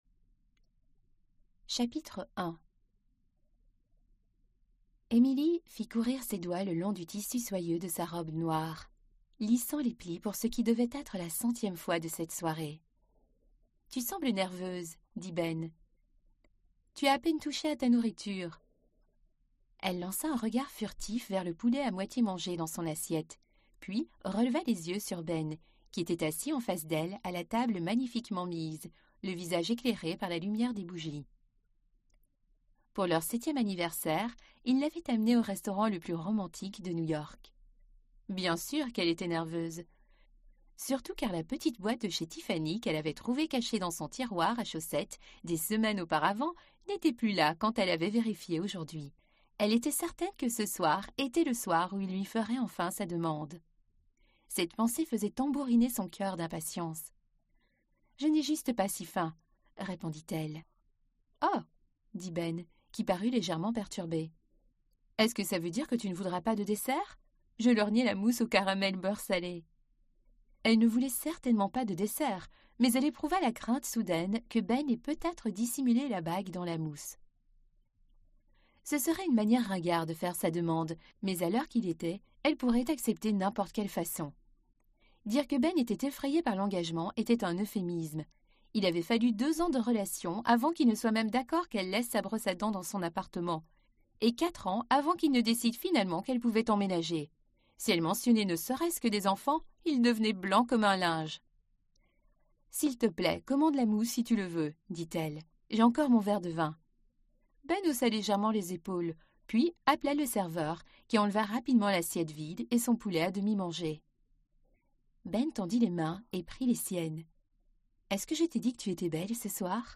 Аудиокнига Maintenant et À Tout Jamais | Библиотека аудиокниг